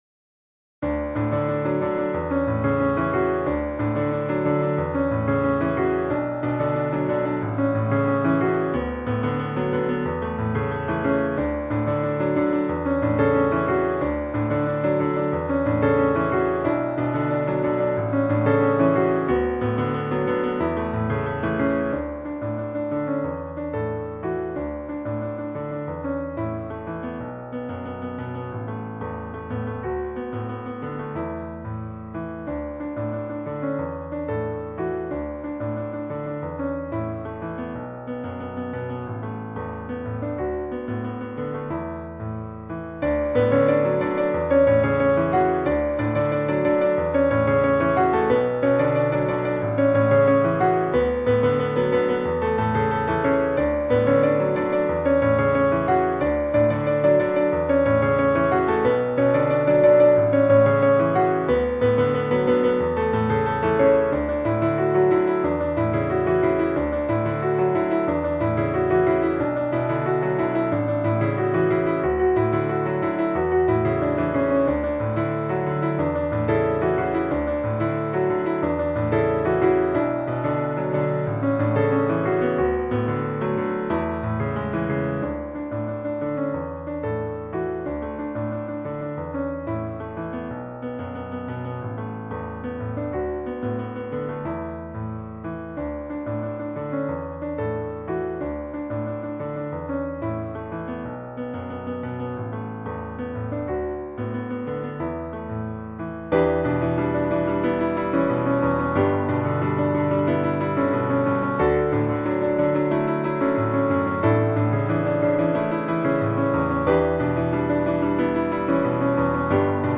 Ноты для фортепиано.